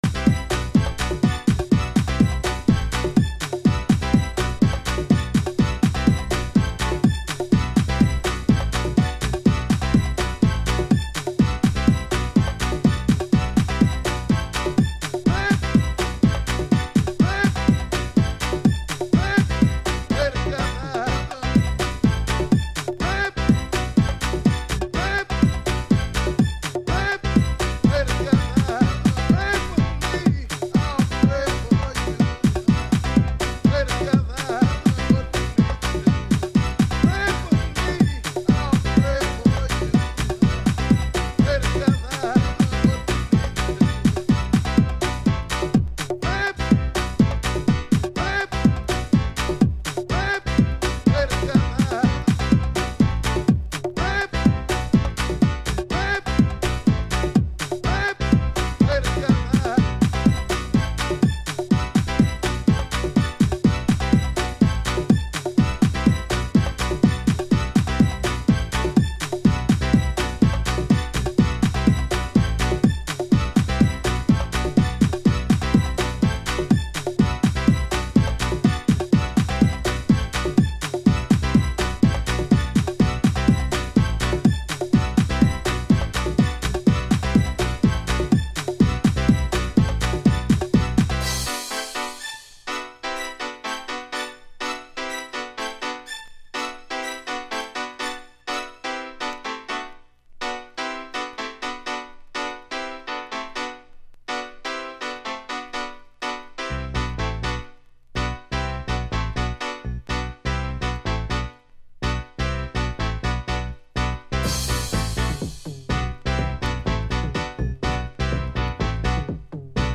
Early House / 90's Techno
アーティスト名からも熱が伝わる、ディープなガラージハウス。